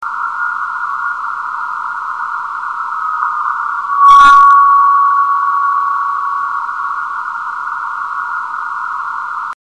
suono di una meteora iperdensa con "eco di testa" (mp3/152Kb)